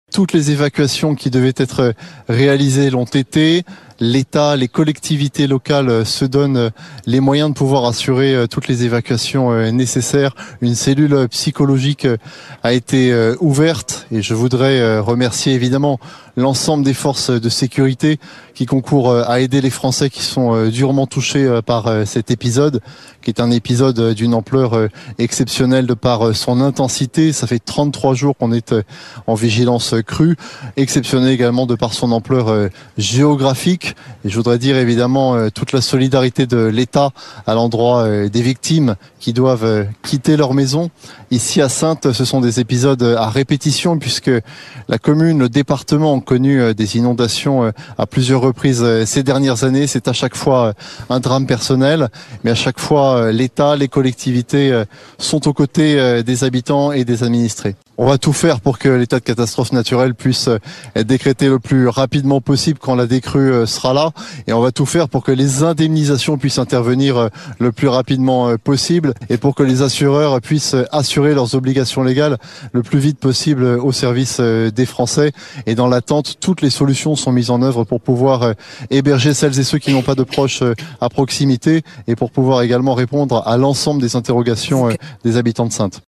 Il a réaffirmé le soutien de l’État face à cette catastrophe d’une ampleur historique pour la ville, digne des crues de 1982 ou 1994. Mathieu Lefevre a déclaré ce matin sur BFMTV que tout sera mis en œuvre pour accompagner le plus rapidement possible les habitants impactés.